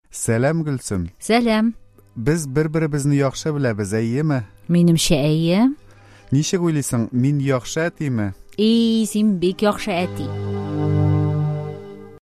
Диалог №1: